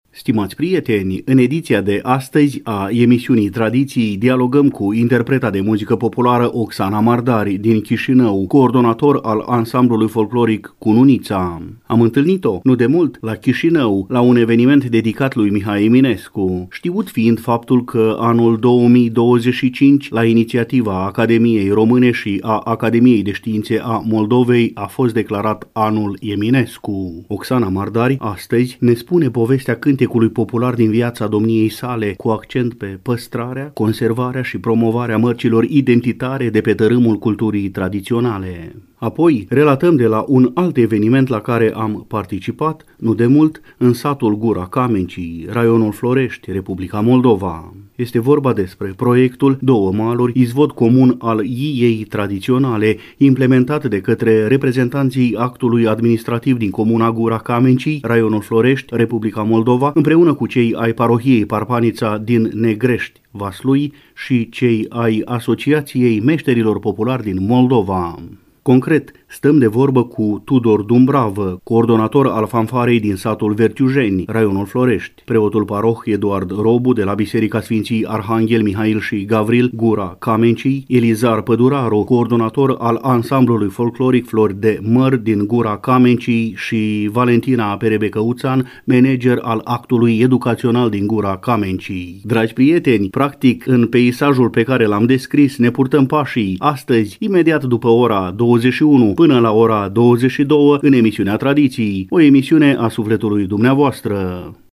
Stimați prieteni, în ediția de astăzi a emisiunii Tradiții dialogăm cu interpreta de muzică populară